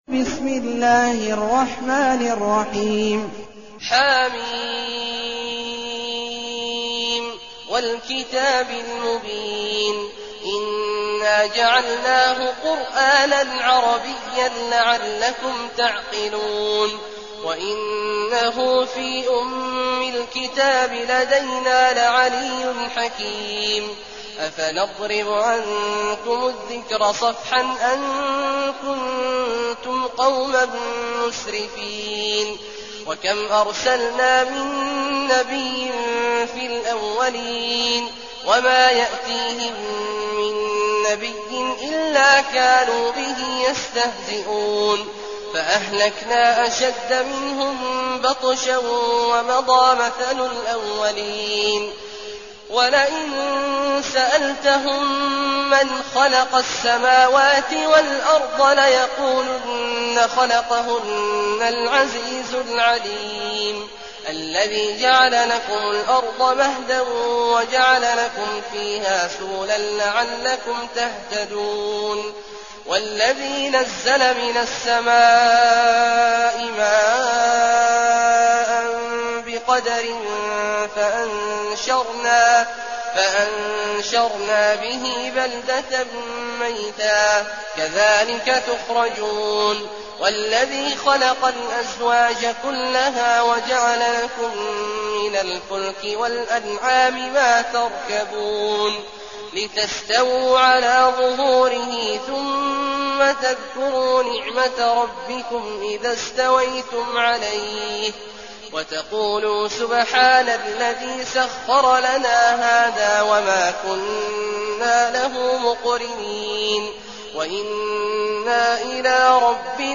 المكان: المسجد الحرام الشيخ: عبد الله عواد الجهني عبد الله عواد الجهني الزخرف